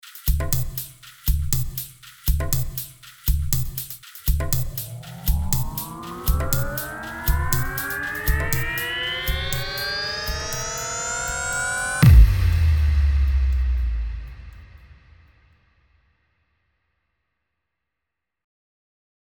Download Best Outro sound effect for free.